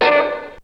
Boink002.wav